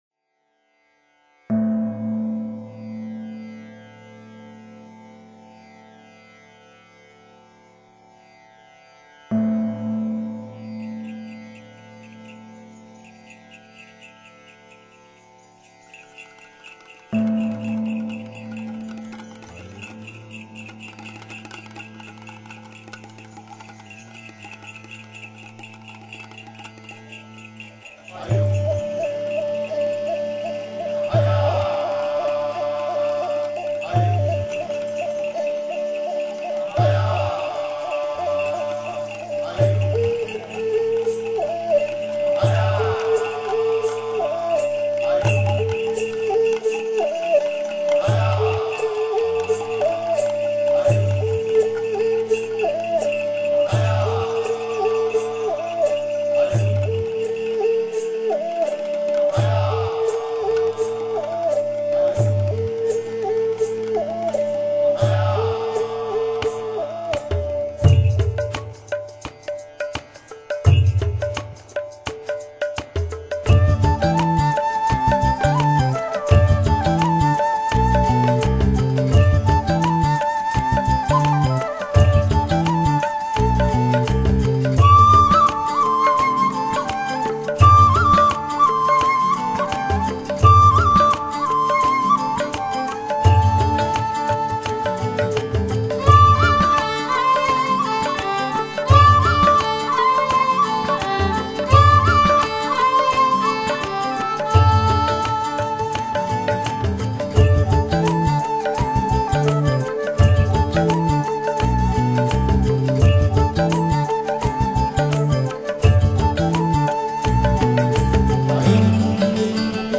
曼尼普尔竹笛、古老的披那、桑图尔琴、尼泊尔手鼓、人声…